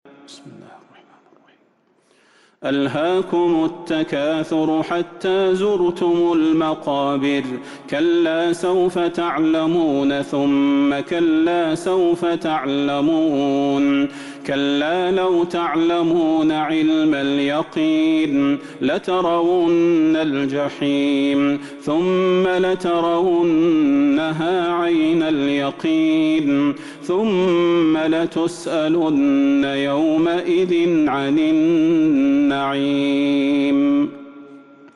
سورة التكاثر Surat At-Takathur من تراويح المسجد النبوي 1442هـ > مصحف تراويح الحرم النبوي عام 1442هـ > المصحف - تلاوات الحرمين